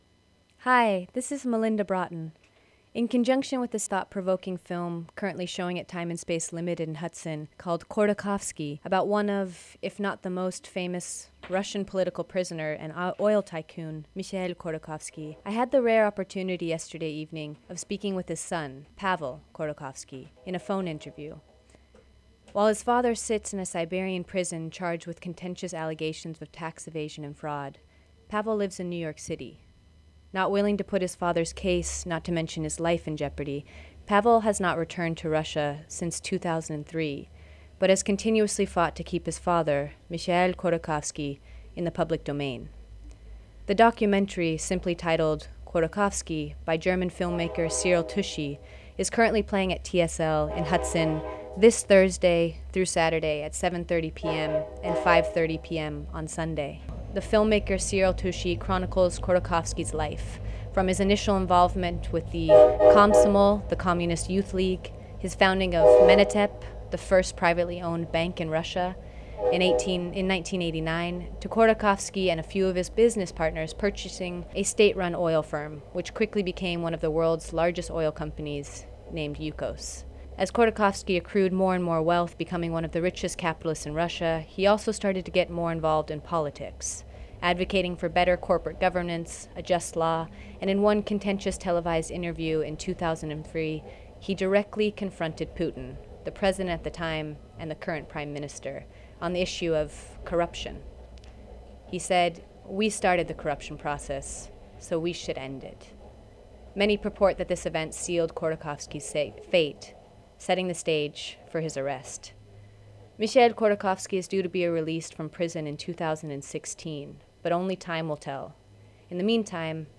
A Conversation
Interview